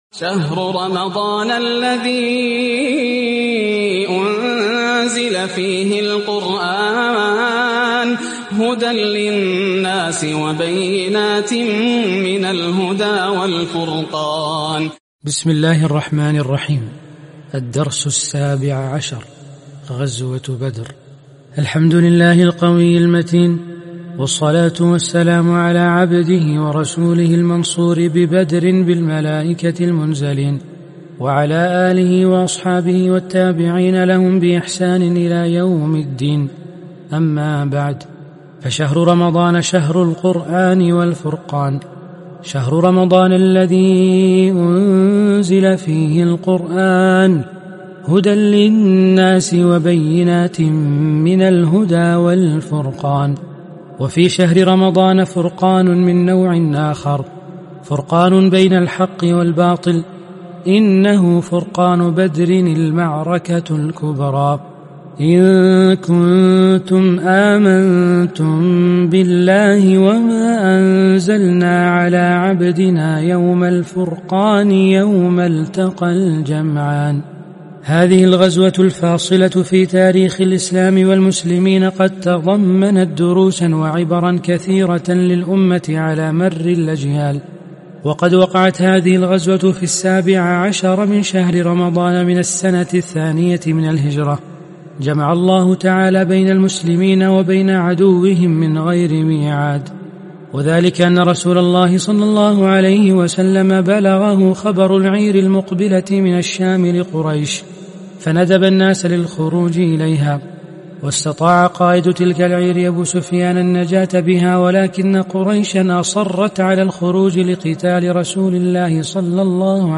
عنوان المادة (18) القراءة الصوتية لكتاب عقود الجمان - (الدرس 17 غزوة بدر)